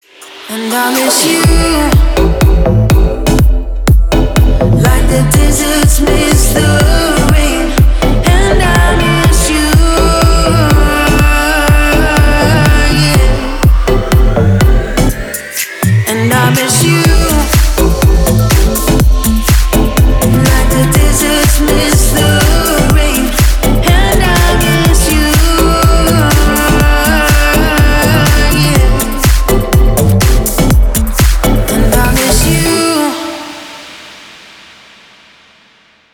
• Качество: 320, Stereo
deep house
Electronic
красивый женский голос